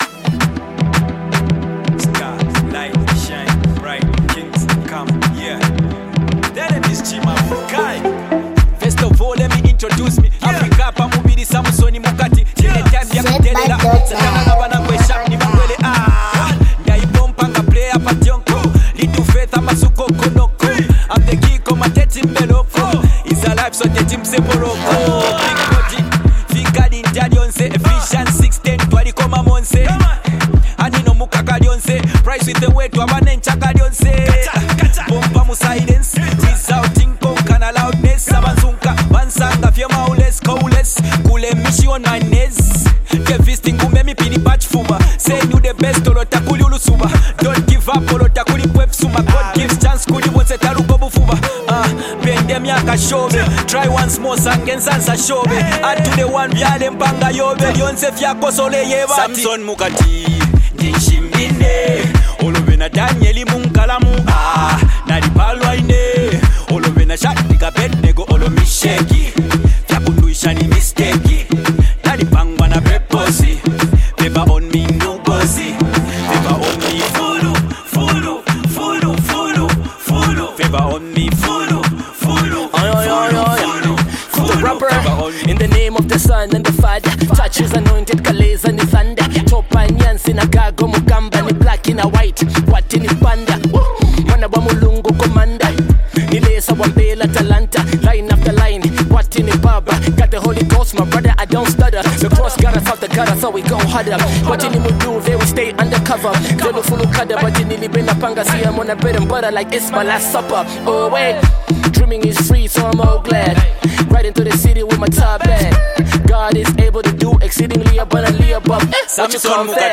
smooth, laid-back beat